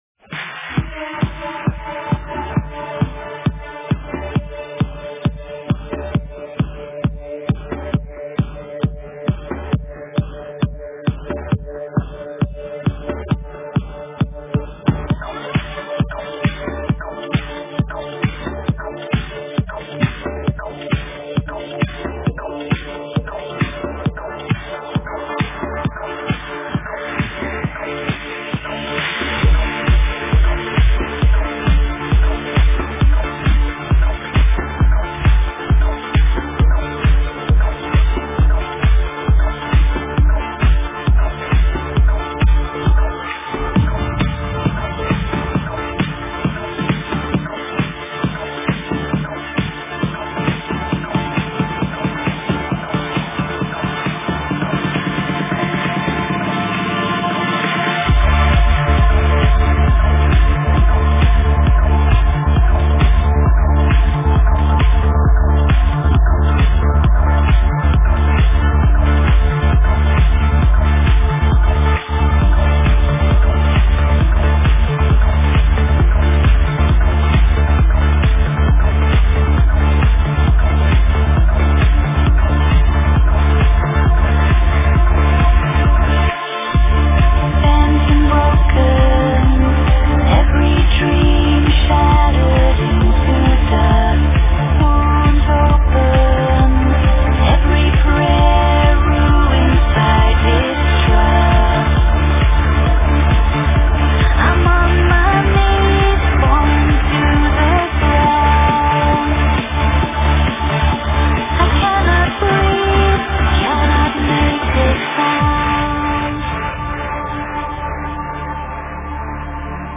Стиль: Progressive Trance / Vocal Trance